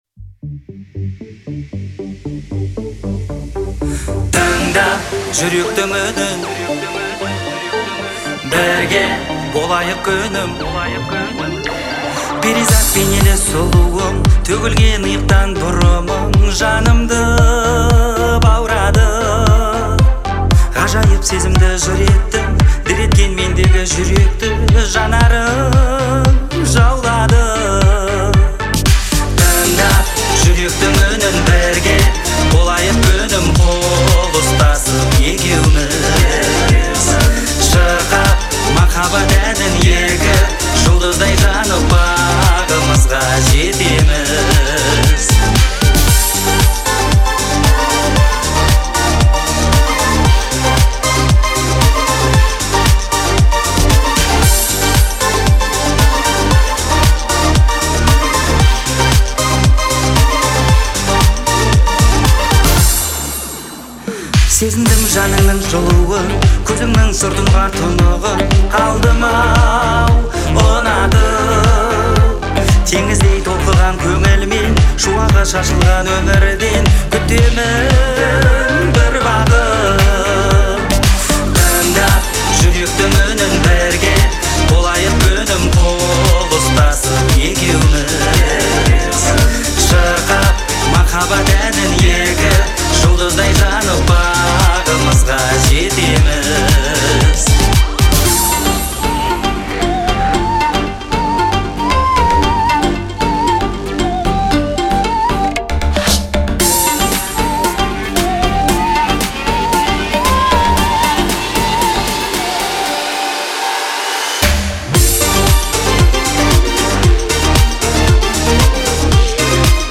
мягкий вокал и выразительные мелодии